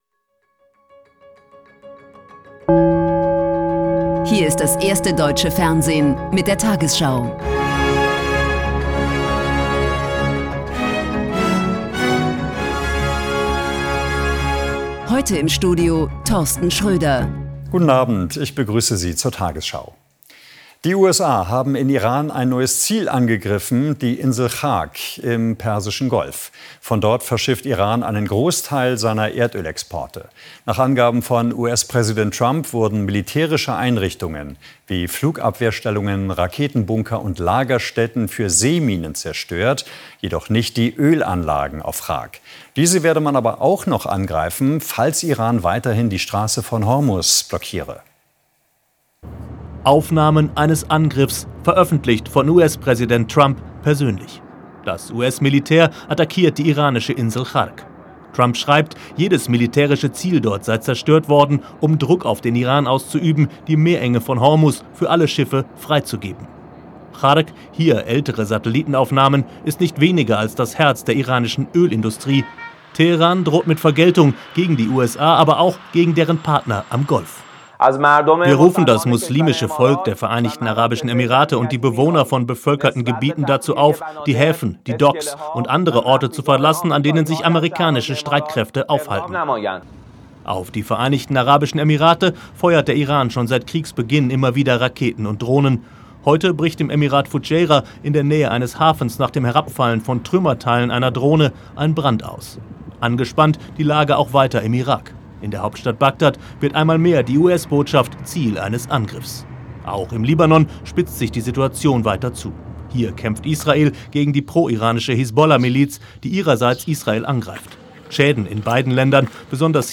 tagesschau: Die 20 Uhr Nachrichten (Audio)